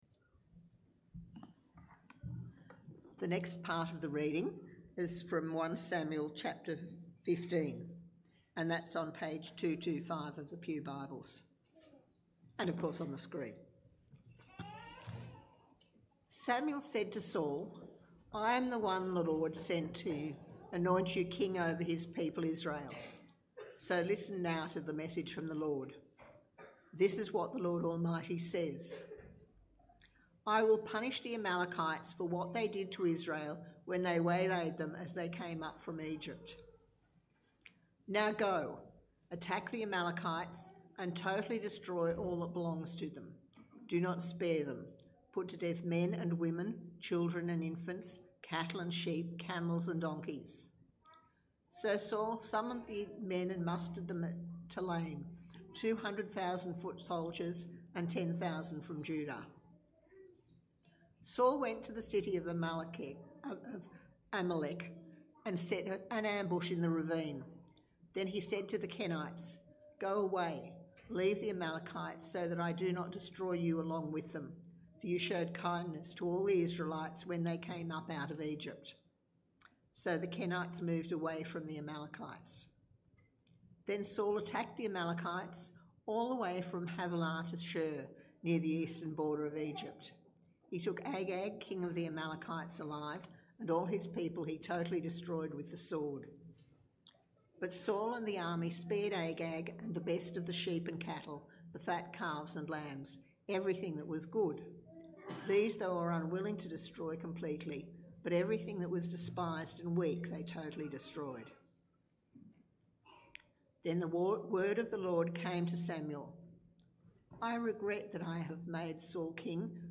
Sermons | Dickson Baptist Church